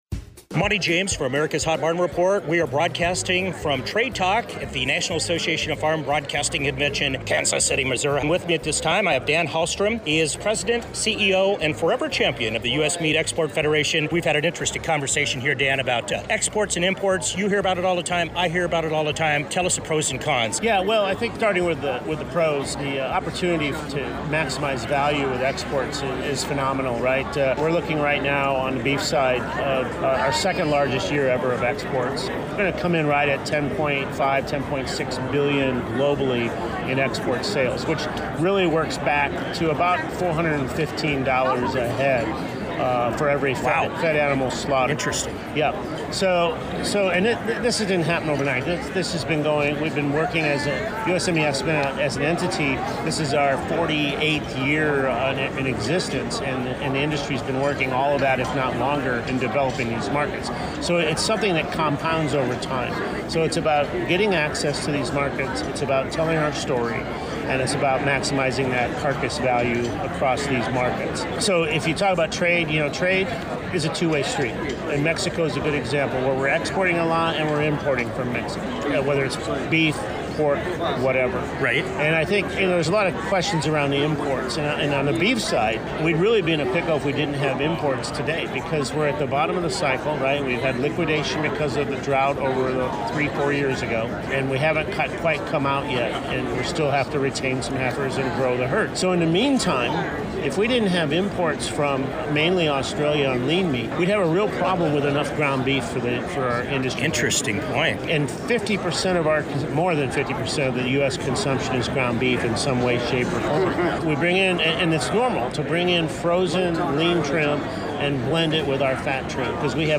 HOT BARN REPORT: Special Interview